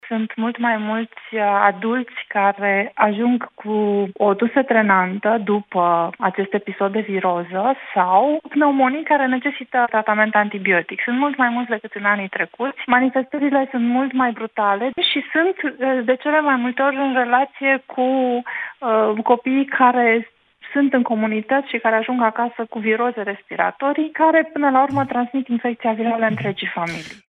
a spus în emisiunea Deșteptarea